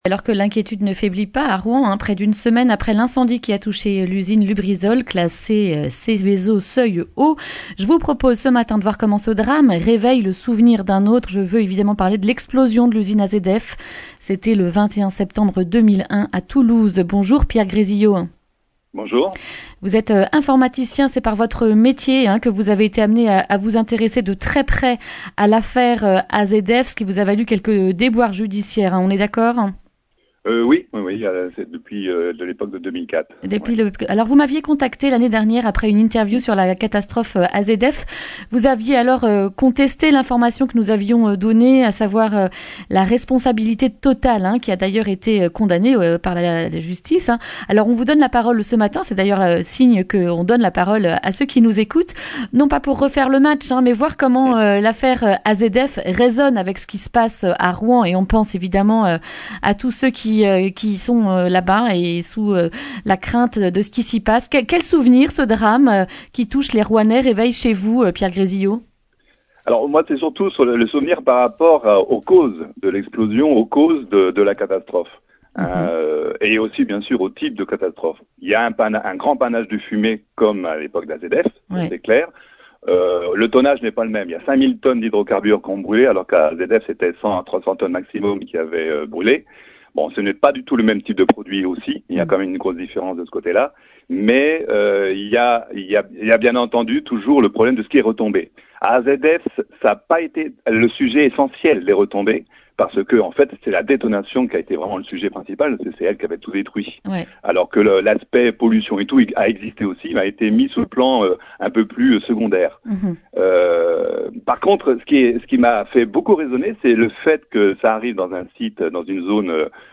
mercredi 2 octobre 2019 Le grand entretien Durée 11 min